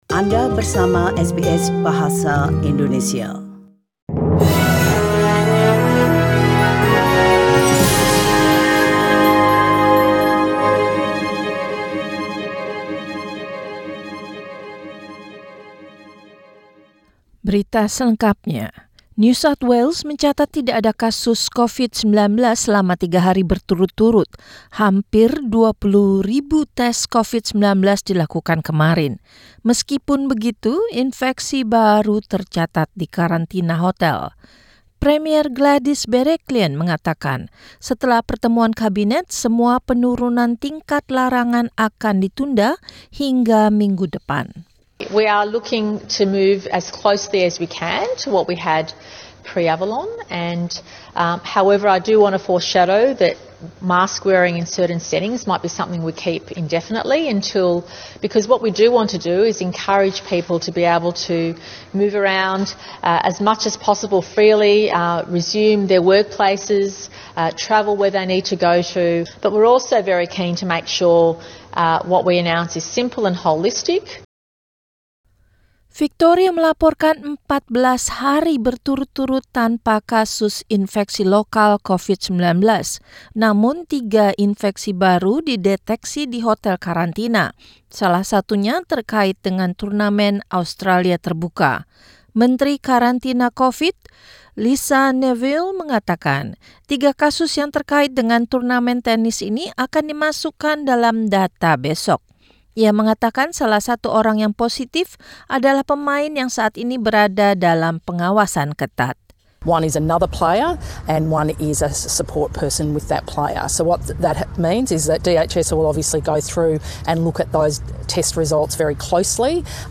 SBS Radio News delivered in Indonesian - 20 January 2021